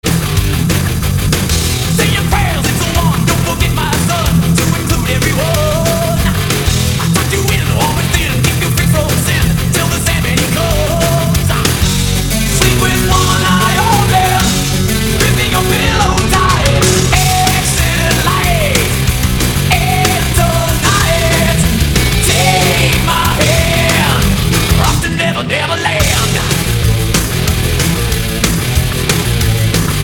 Драйвовые
90-е
heavy Metal
цикличные
Драйвовый хеви-метал